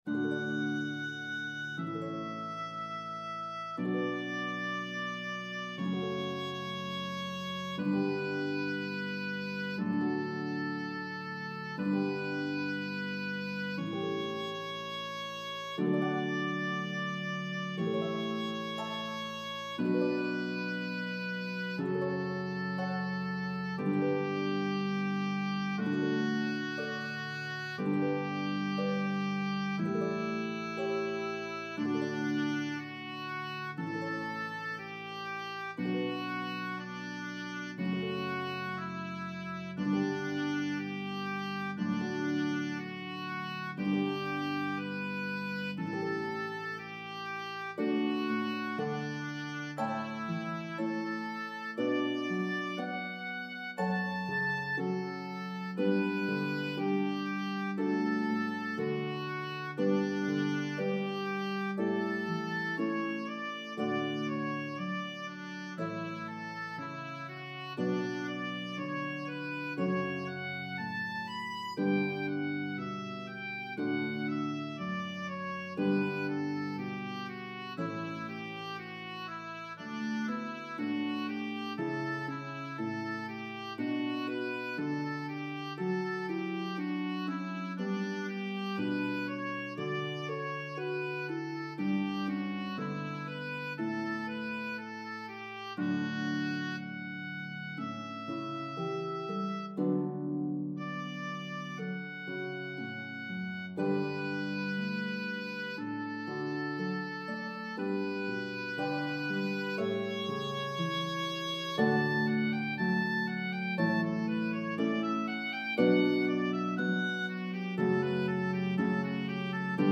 Harp and Oboe version